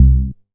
MoogEatBass B.WAV